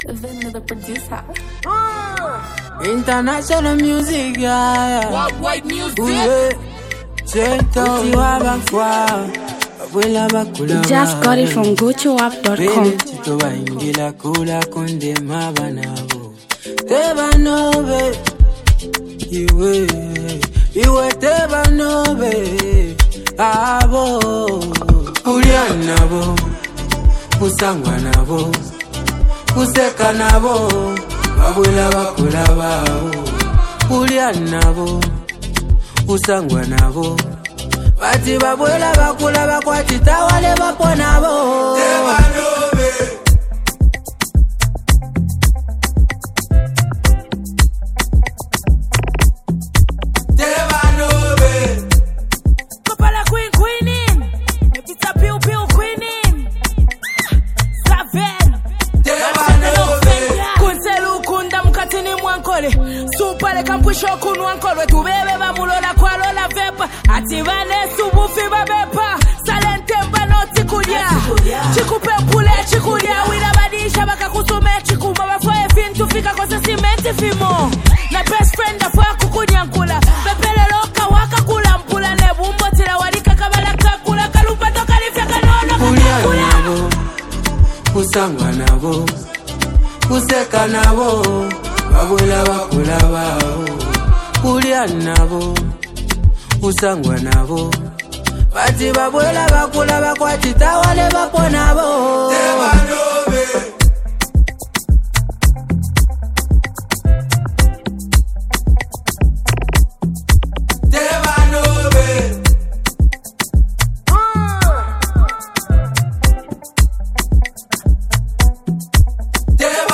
this song brings a fresh sound to the Zambian music scene.